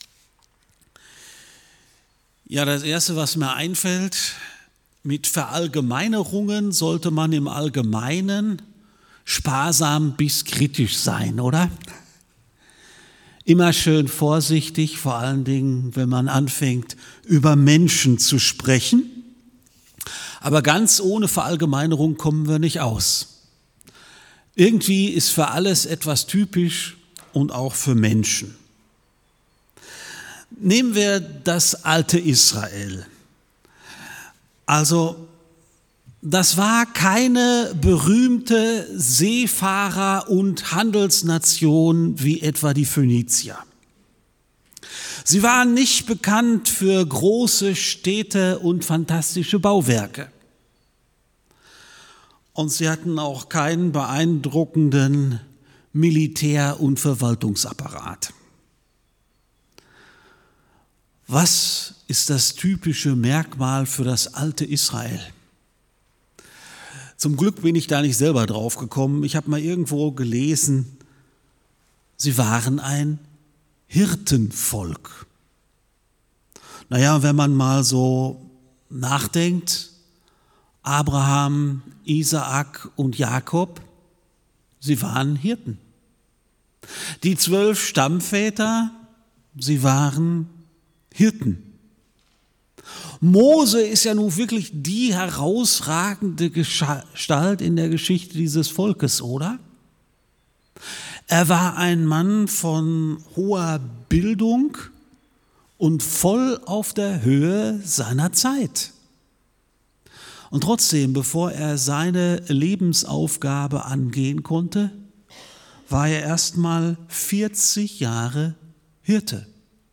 Predigt Podcast